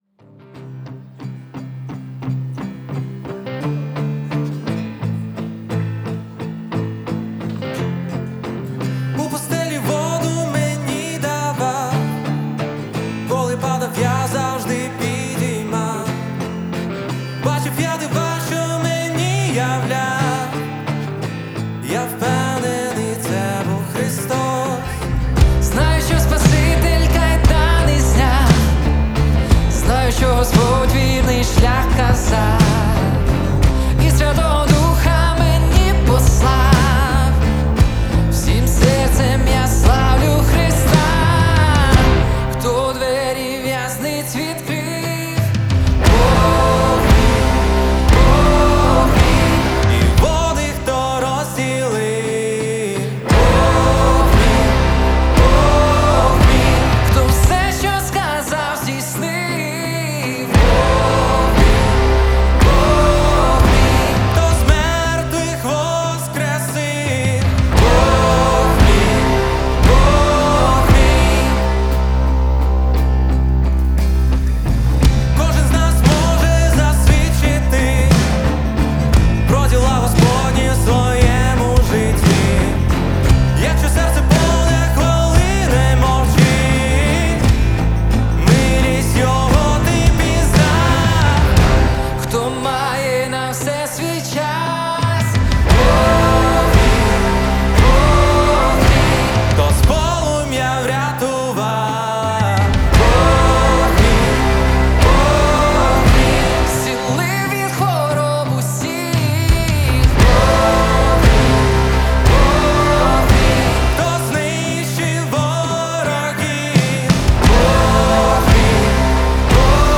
343 просмотра 242 прослушивания 13 скачиваний BPM: 87